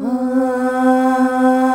AAAAH   C.wav